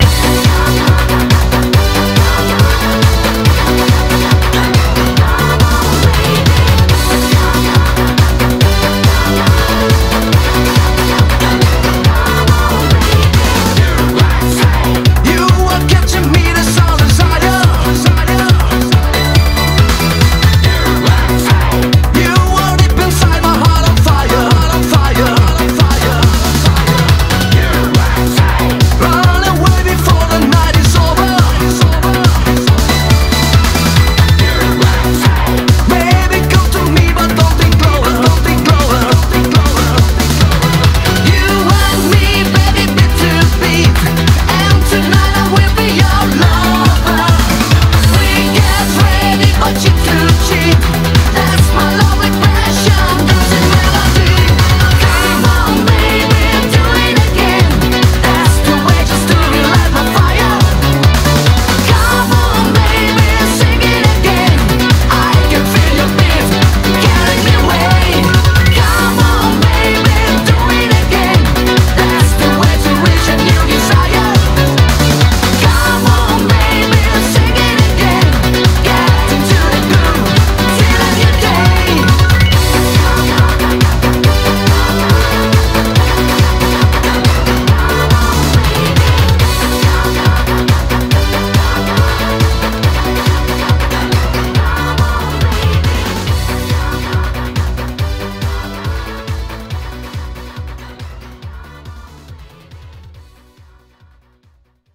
BPM140
Audio QualityPerfect (High Quality)
Comments[EUROBEAT]